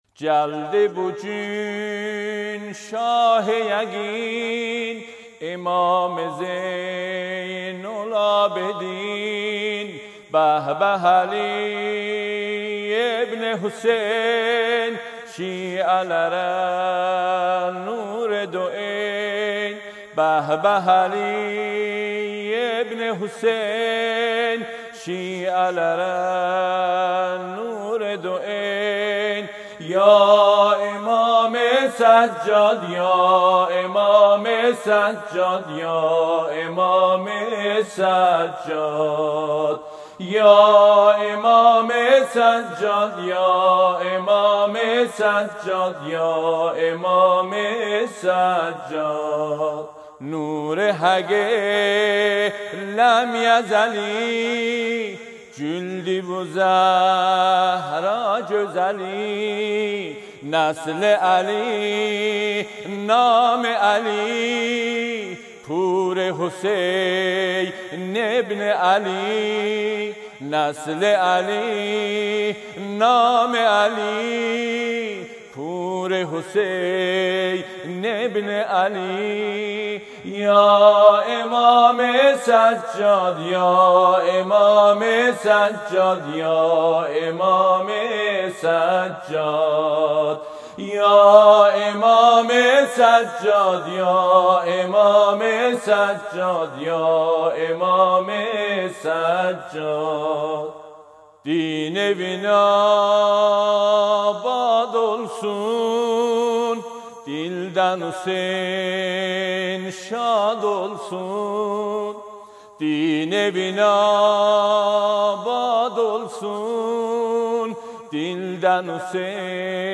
مولودی آذری مولودی ترکی